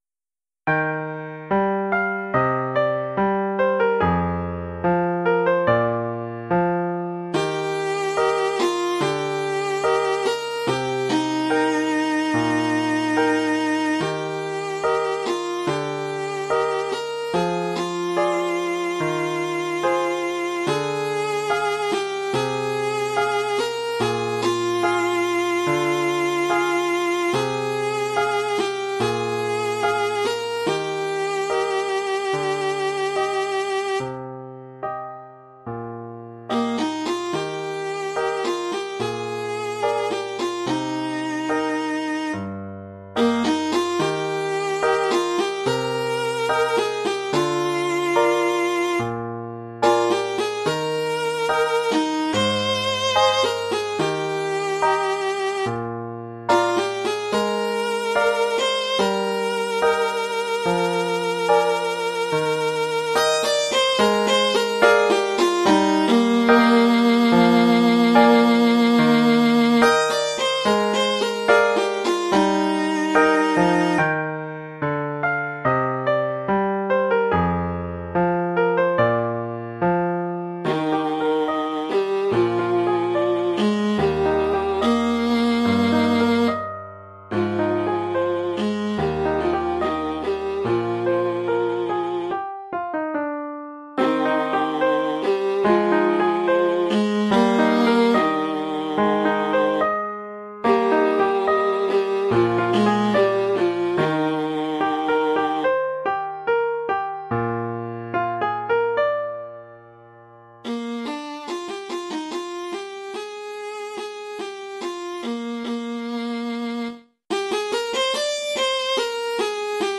Oeuvre pour alto et piano.